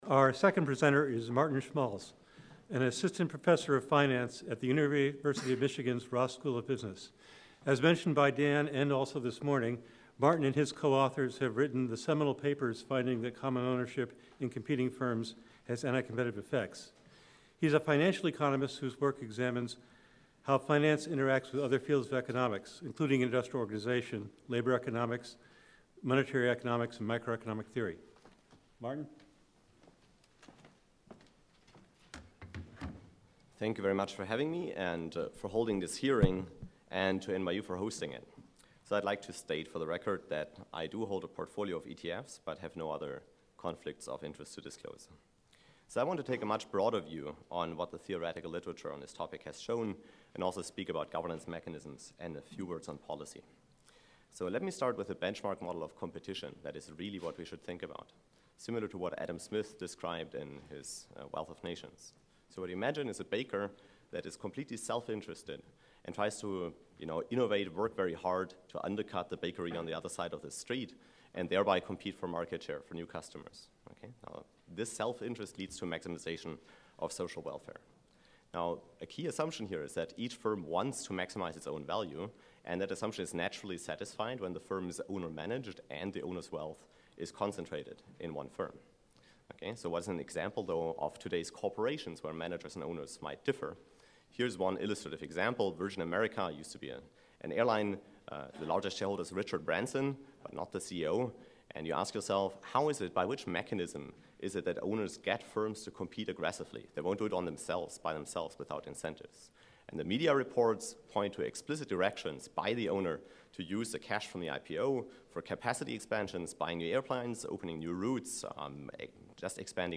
The theme of the hearing was "Common Ownership."